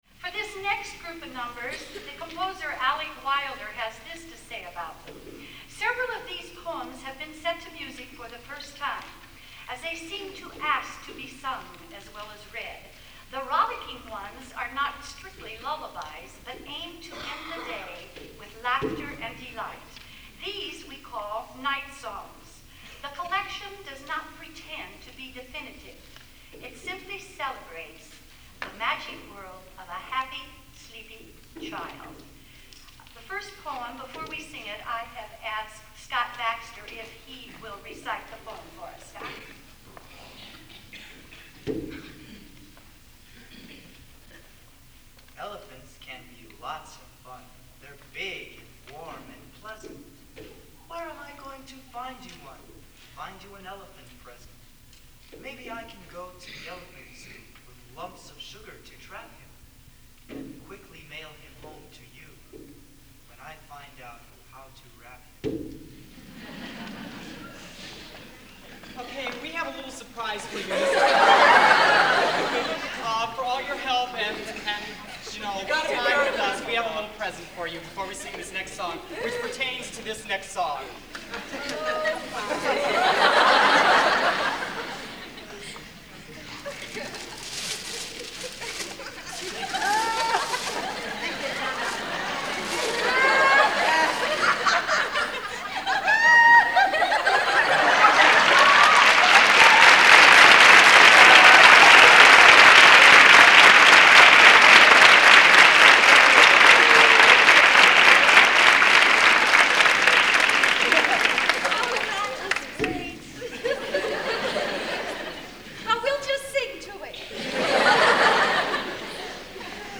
Spring Concert
CHS Auditorium